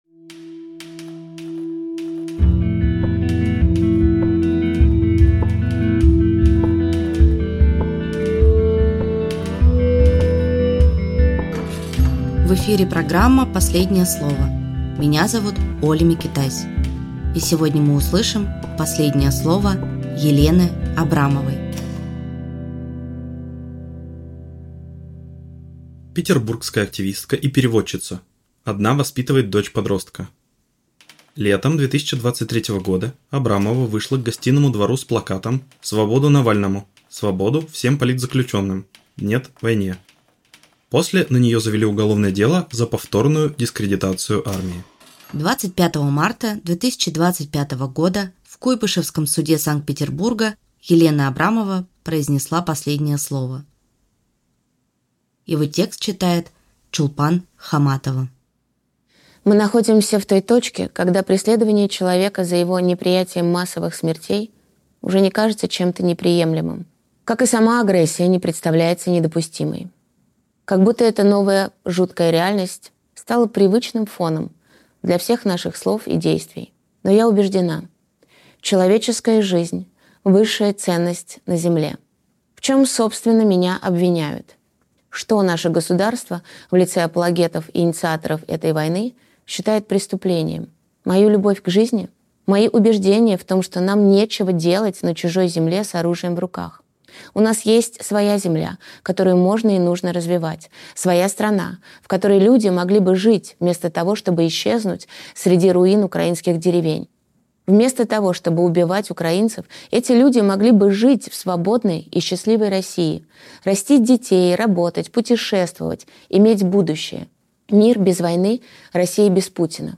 Чулпан Хаматоваактриса
Читает Чулпан Хаматова.